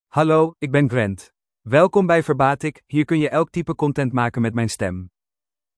Grant — Male Dutch AI voice
Grant is a male AI voice for Dutch (Netherlands).
Voice sample
Listen to Grant's male Dutch voice.
Grant delivers clear pronunciation with authentic Netherlands Dutch intonation, making your content sound professionally produced.